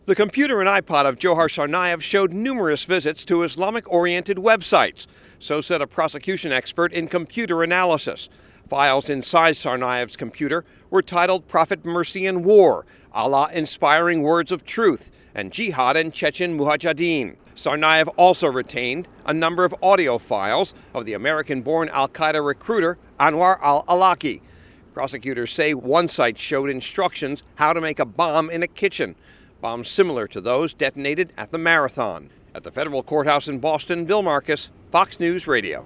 HAS MORE FROM BOSTON.